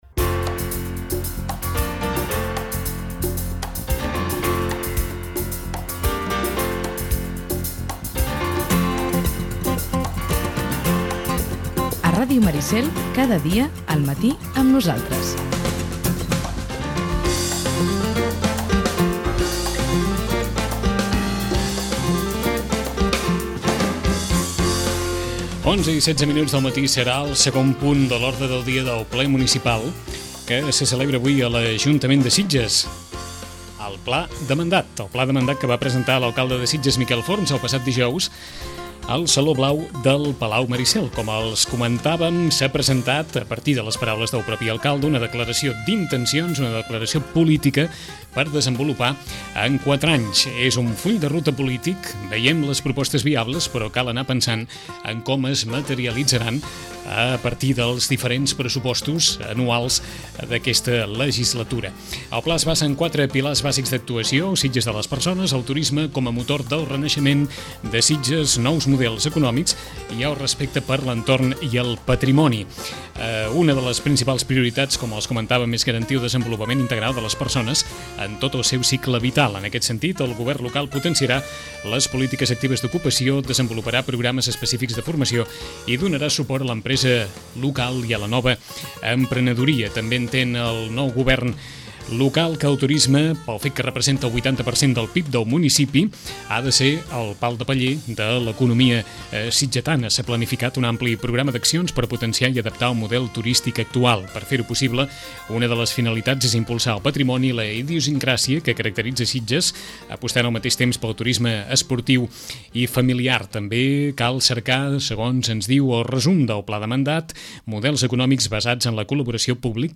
Us oferim integrament l’acte d’exposició del pla de mandat 2011-2015, que l’alcalde Miquel Forns presentà el passat dijous al saló blau del palau Maricel.